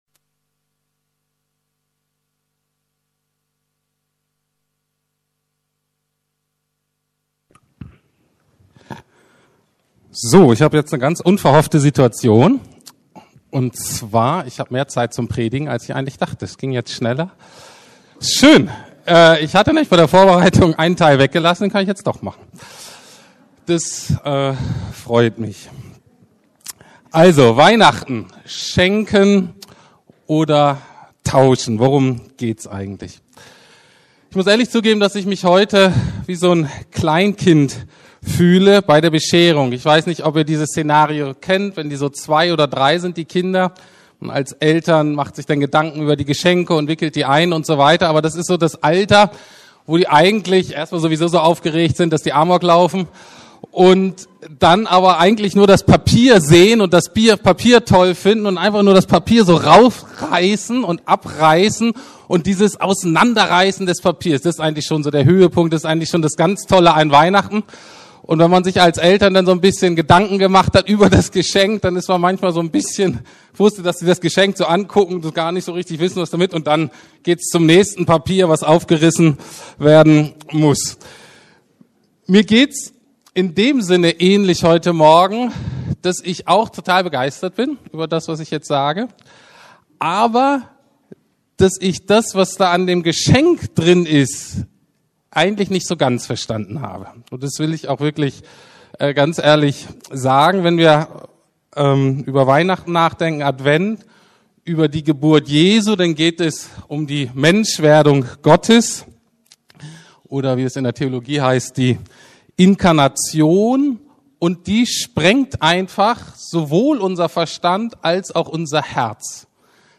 Weihnachten Schenken oder tauschen ~ Predigten der LUKAS GEMEINDE Podcast